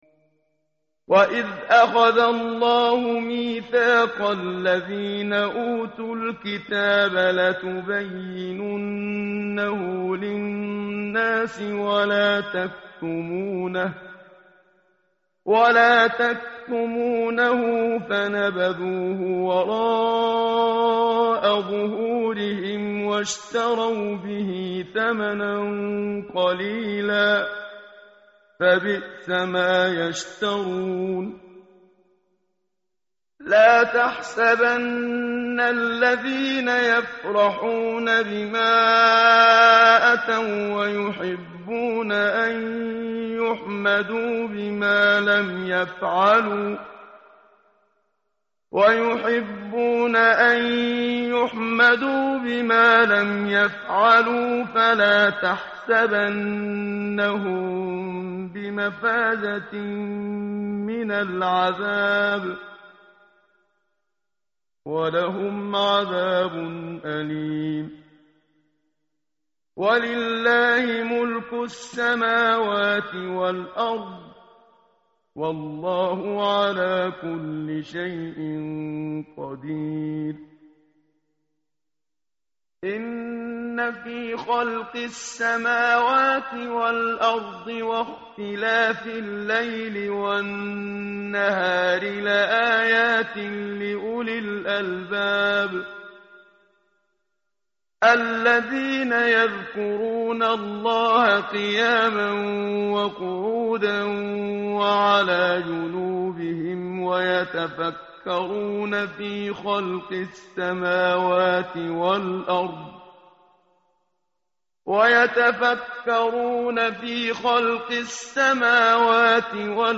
متن قرآن همراه باتلاوت قرآن و ترجمه
tartil_menshavi_page_075.mp3